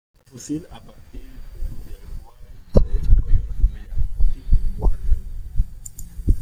/fuˈsil/